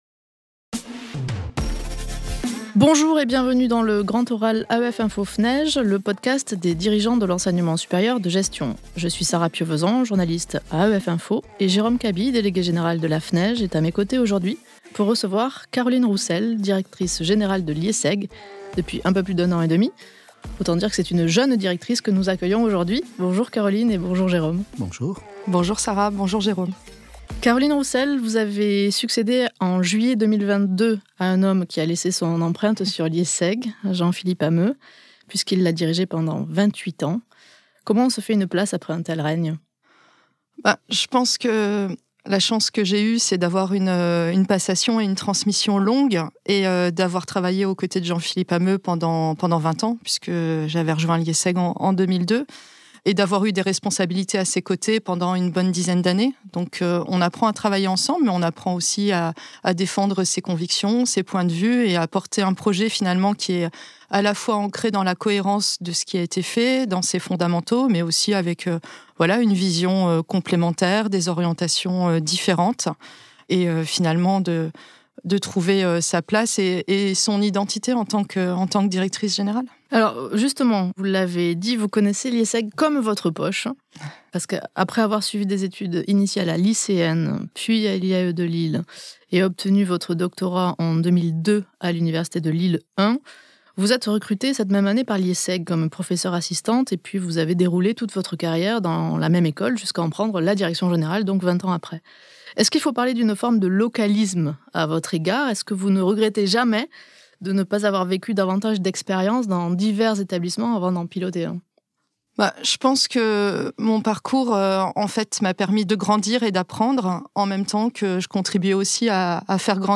Elle est interrogée sur l'avenir du format postbac en 5 ans à l'heure de la mode des bachelors, sur la typologie du recrutement étudiant de l'école, et sur le défi de l'ouverture sociale quand les coûts de scolarité atteignent 64500 euros sur l'ensemble du parcours "grande école".